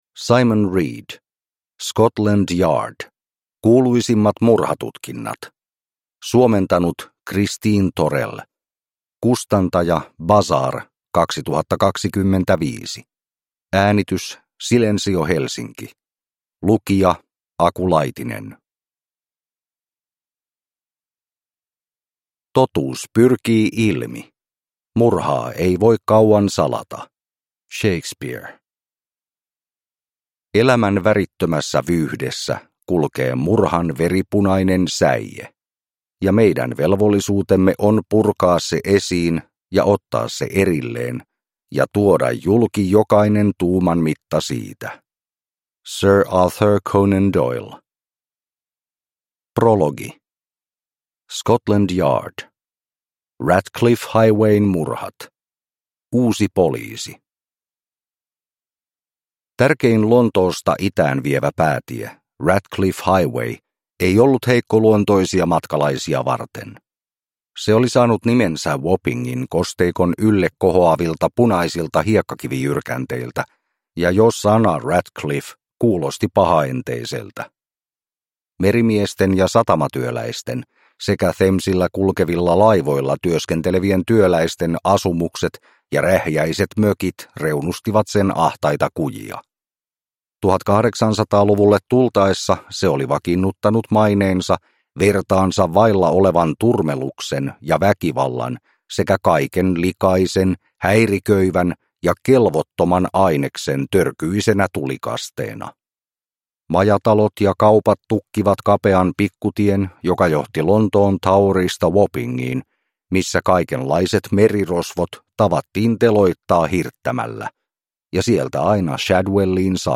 Scotland Yard: Kuuluisimmat murhatutkinnat – Ljudbok